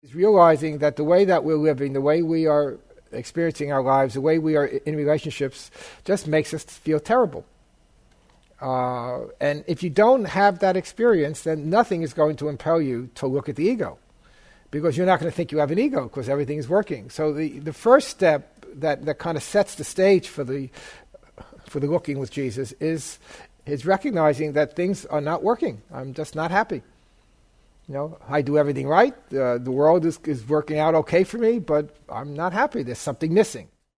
This self-contained one-day class was part of a larger class on a different topic.
The answer, augmented by readings from A Course in Miracles and additional questions, gave rise to this set. Looking with Jesus is the meaning of forgiveness, which undoes the ego’s judgment of guilt that leads to projection.